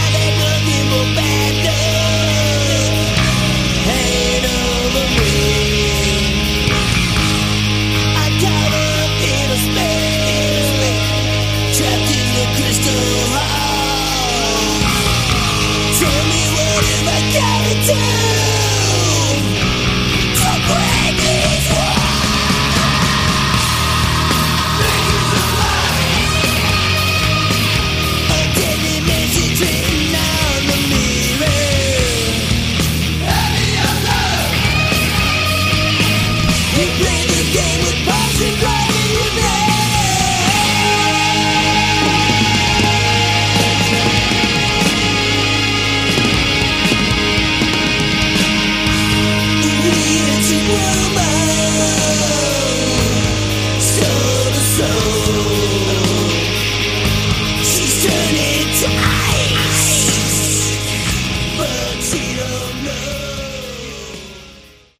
Category: Hard Rock
bass, backing vocals
guitars, keys, harp
lead and backing vocals